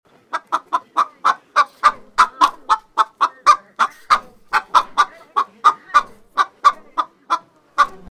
Sound Effects
Chicken Clucking Type 1